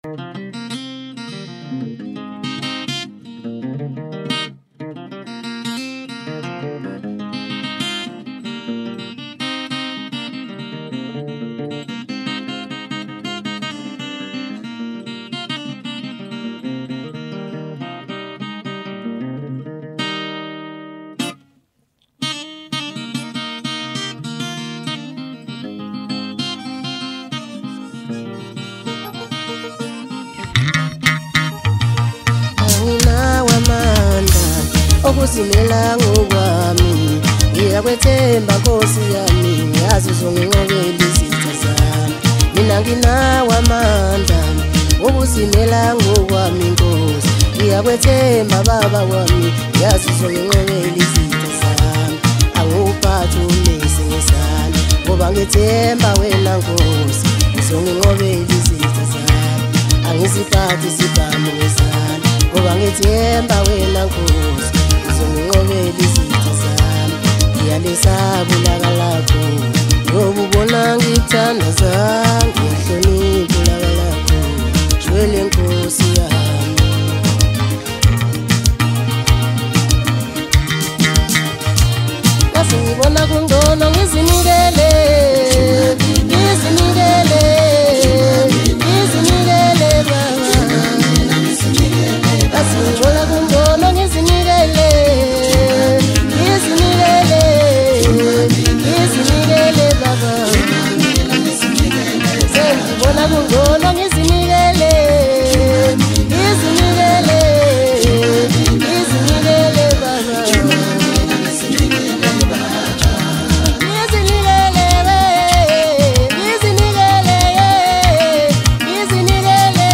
Home » DJ Mix » Hip Hop » Maskandi